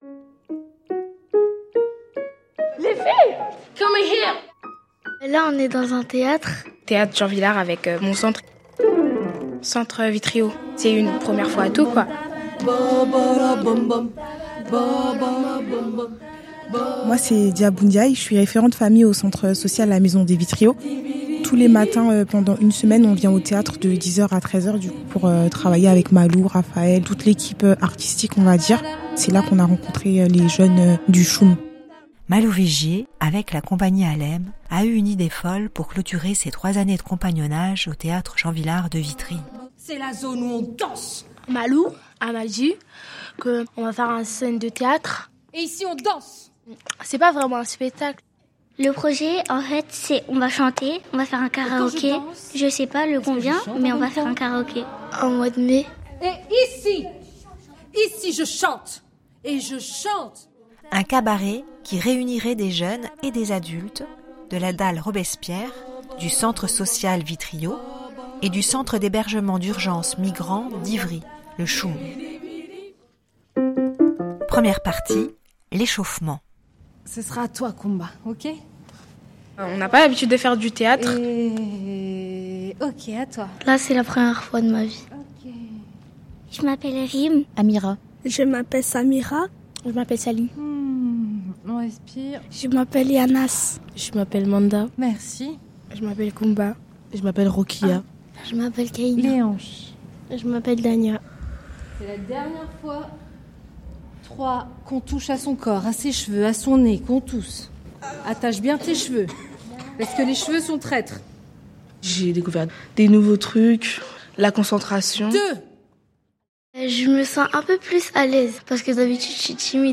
Le podcast ci-dessous raconte cette aventure de l’intérieur. On y entend des voix, des bouts de répétitions, des idées, des rencontres, des rires aussi.